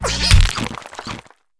Cri de Forgerette dans Pokémon HOME.